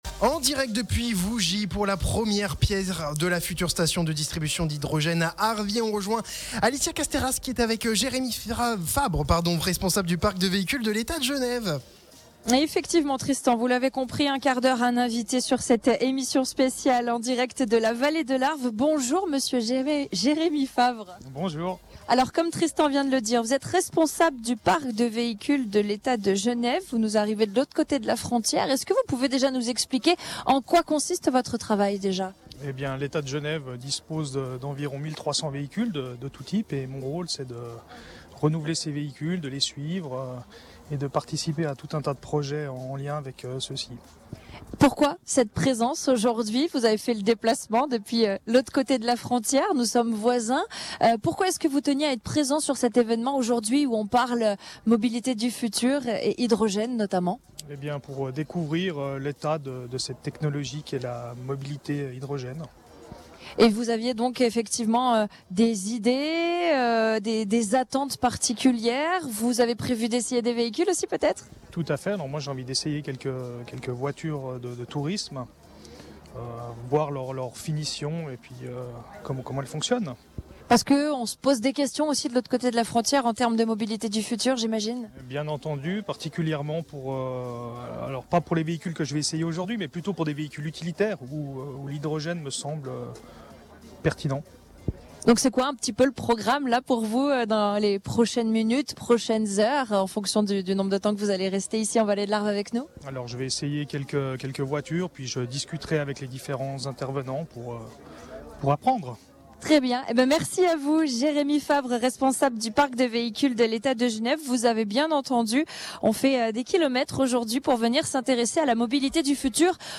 Ce jeudi 27 juin, Radio Mont Blanc était en direct de Vougy pour une émission spéciale à l’occasion de la pose de la première pierre de la future station multi-énergies Arv’Hy.